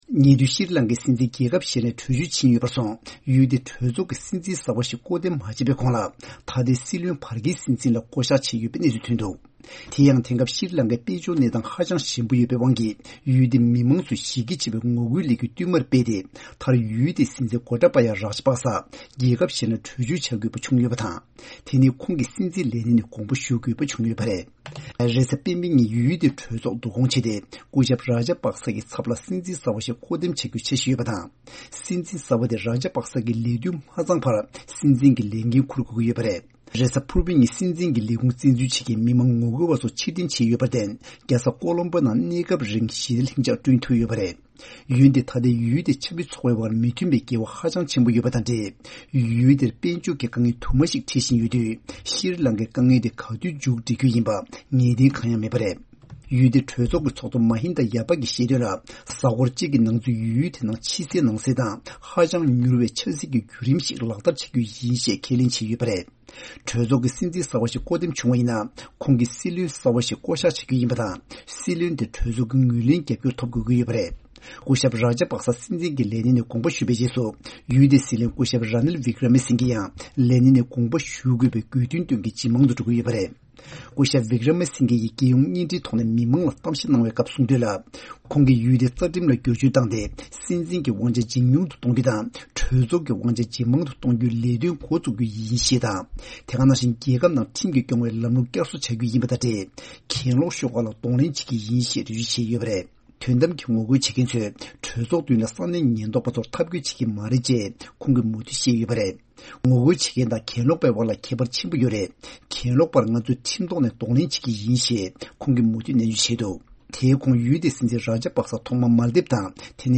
གནས་ཚུལ་སྙན་སྒྲོན་ཞུས་པ་ཡི་རེད།